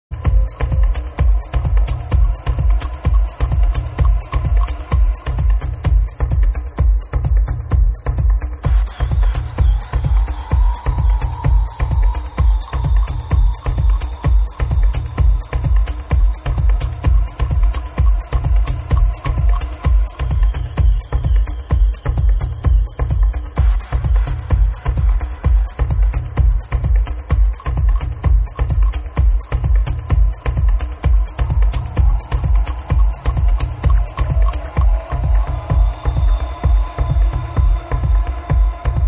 Prog trance tune needs ID